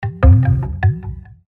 SMS_lub_MMS_4.mp3